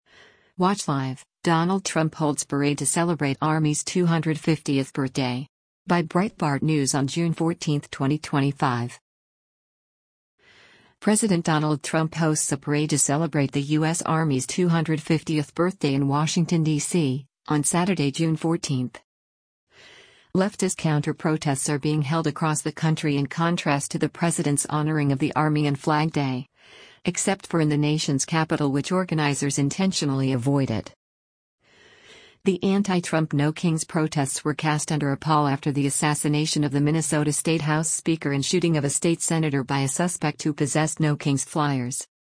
President Donald Trump hosts a parade to celebrate the U.S. Army’s 250th birthday in Washington, DC, on Saturday, June 14.